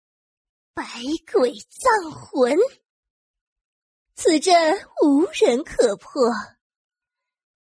女声 游戏 守望先锋全女英雄模仿-8小美